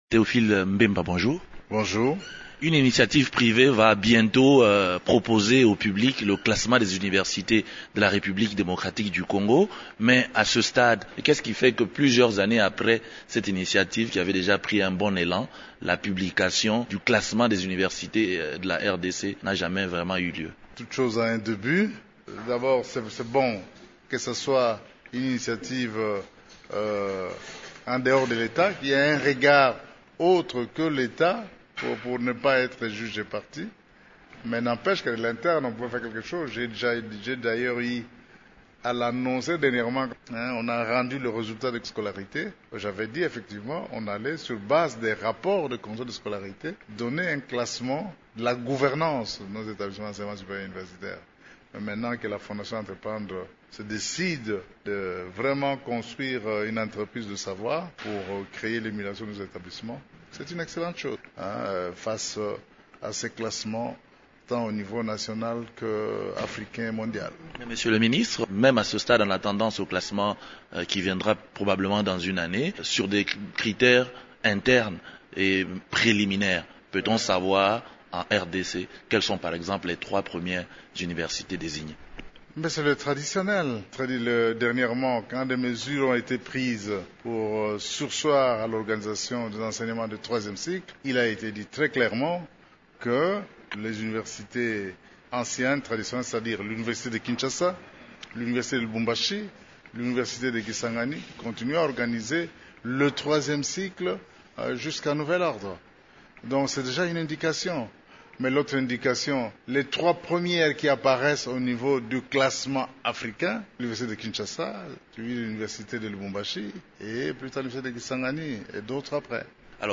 Le ministre de l’Enseignement supérieur et universitaire s’étend sur les détails et les enjeux de ce projet.